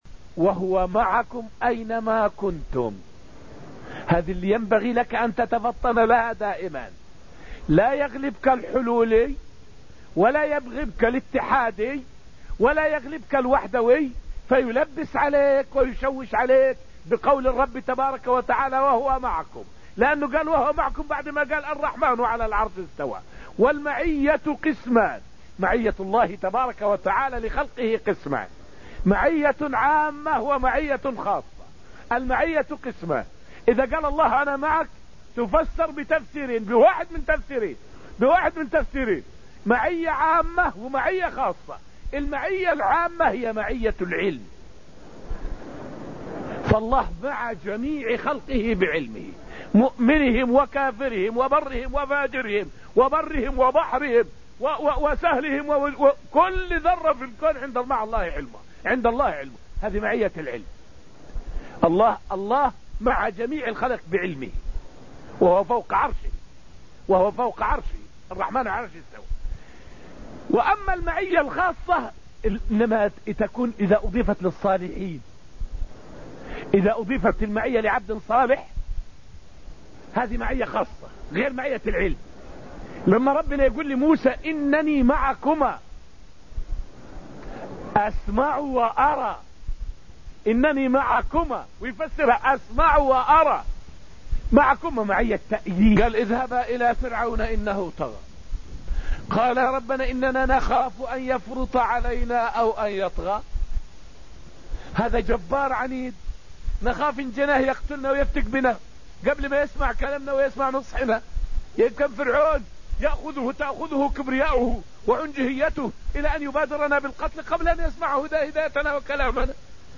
فائدة من الدرس التاسع من دروس تفسير سورة الذاريات والتي ألقيت في المسجد النبوي الشريف حول التوحيد وأنه سبب سعادة الإنسان.